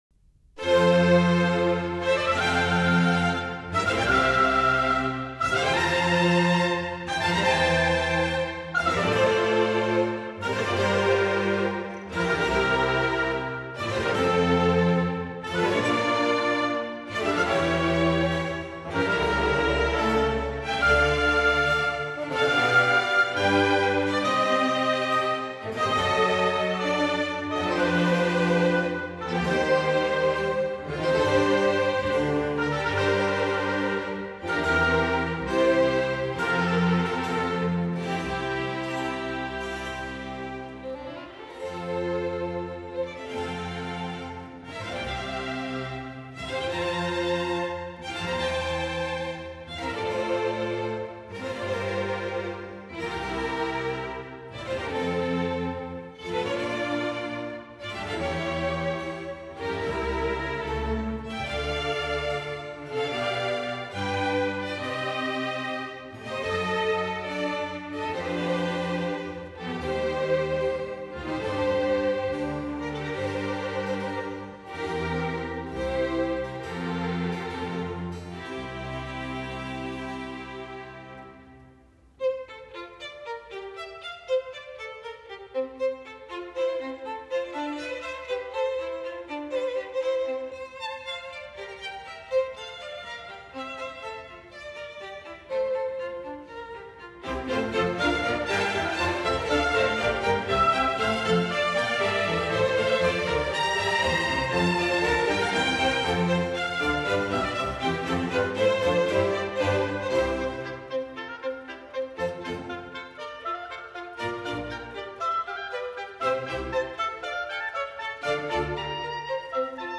序曲
规模宏大的F大调引子乐章用常见的法国序曲的巴洛克形式，以华丽的广板(Largo)开始，然后是一个活泼的赋格段。